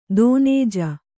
♪ dōnēja